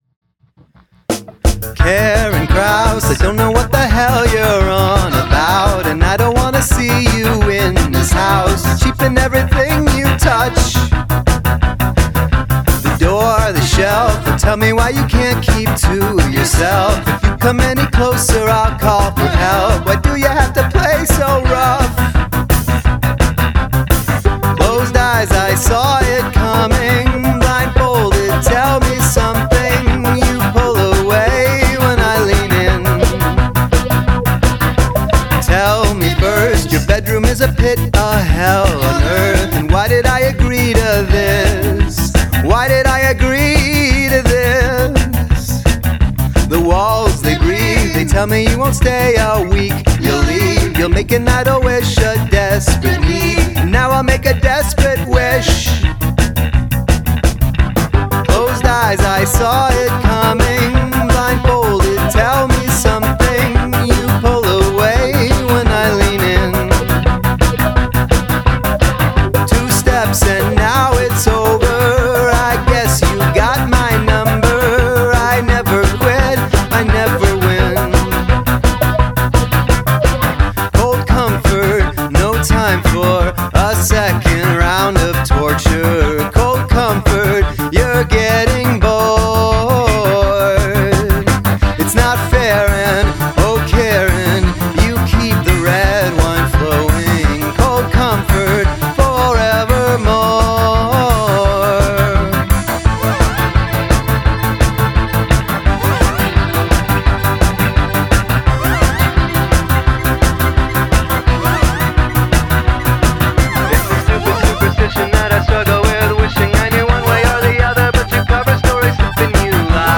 The drums and the bass are not quite right.
+++ slick and catchy +++